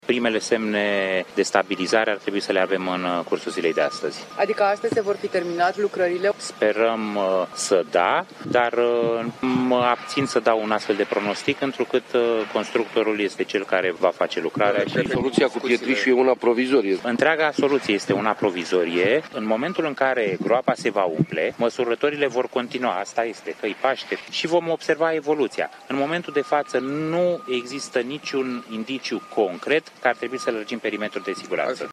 Craterul format în urma surpării unei străzi va fi umplut cu pietre. Prefectul judeţului, Emil Drăgănescu, spune că, în momentul de faţă, nu există niciun indiciu de extindere a surpării .
03mai-15-Prefect-Prahova-solutie-provizorie.mp3